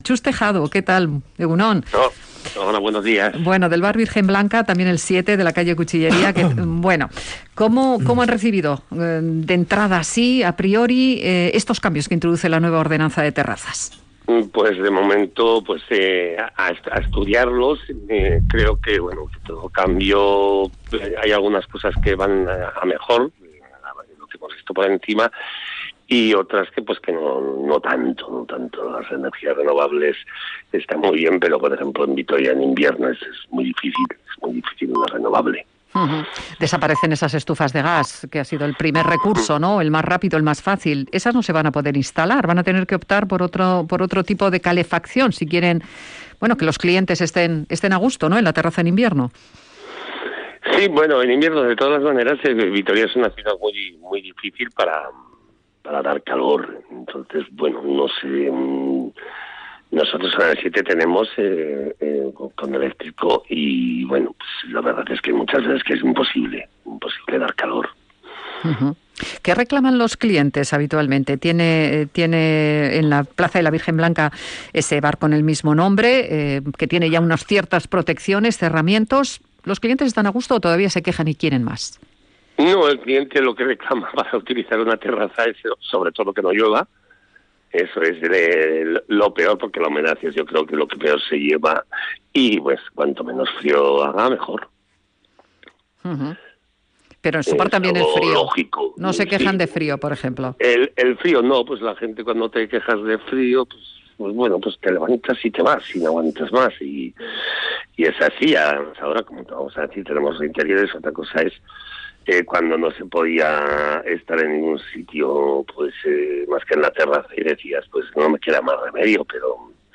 Hoy en Araba Gaur hemos preguntado a dos empresarios de la hostelería gasteiztarra si instalar terrazas con cerramientos fijos para atraer clientes en invierno resulta o no rentable